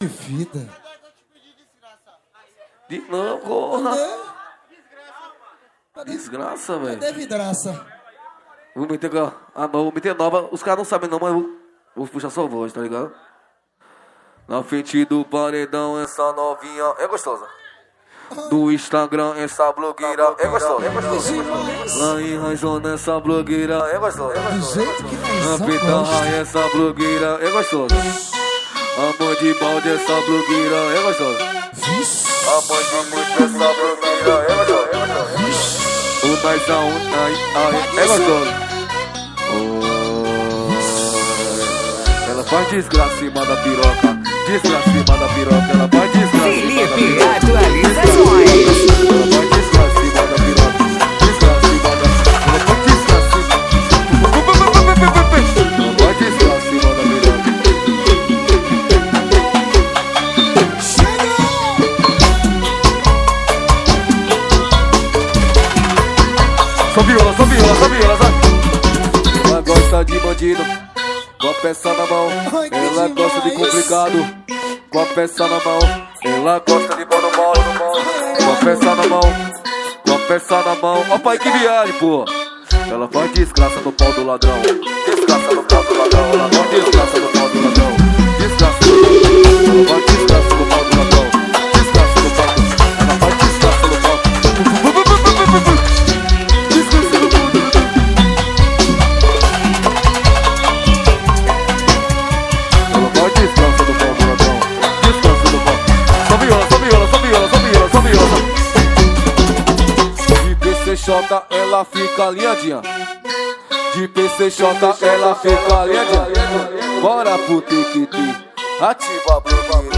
2024-12-27 10:34:27 Gênero: MPB Views